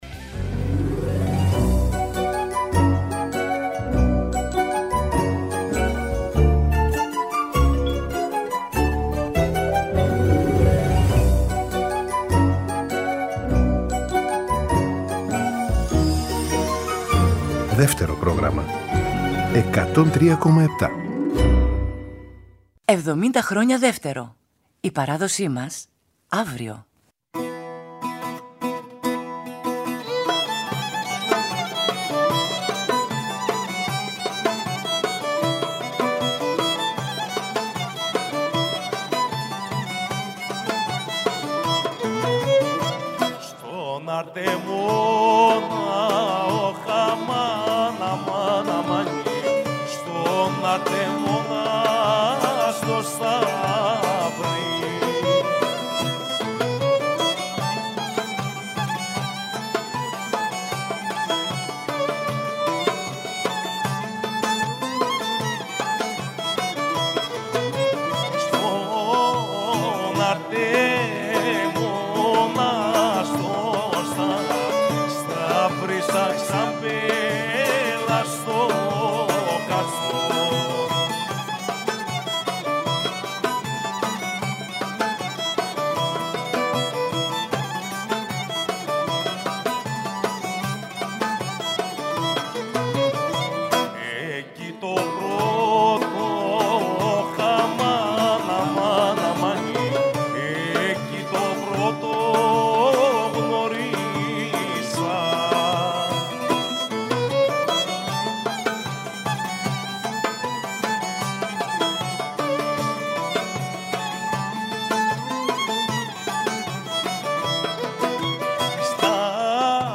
Λαούτο, φωνή
Κλαρίνο, γκάιντα, κιθάρα, φωνή
Κρουστά
Βιολί